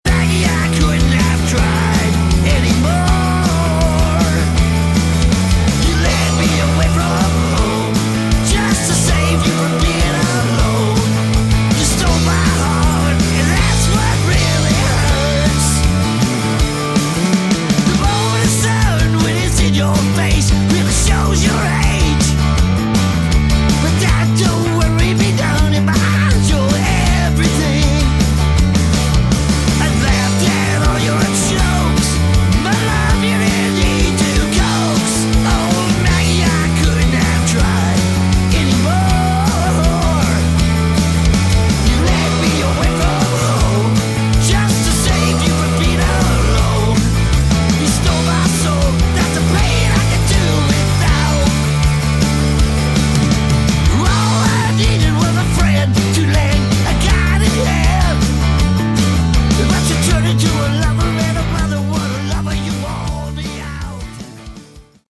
Category: Sleaze Glam/Punk
lead vocals, guitars
bass, piano, mandolin, percussion, backing vocals
drums, backing vocals
guitar, ebow, backing vocals
acoustic guitar, electric steel, backing vocals